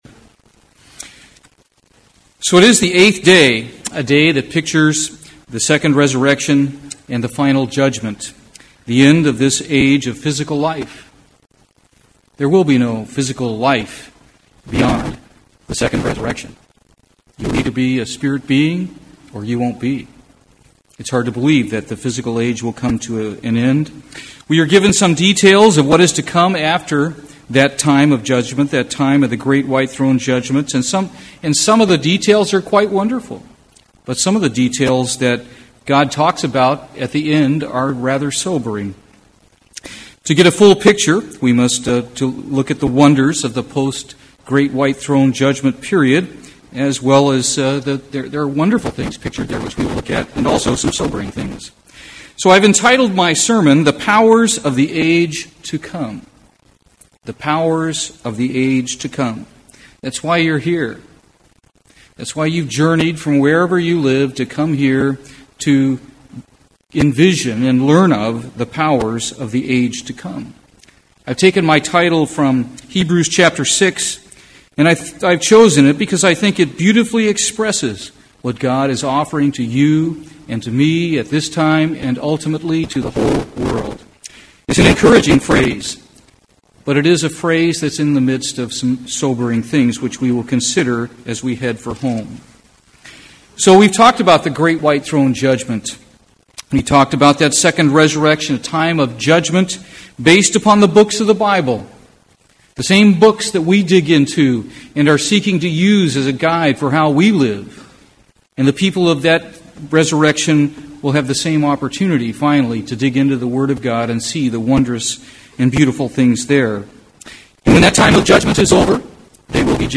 This sermon was given at the New Braunfels, Texas 2012 Feast site.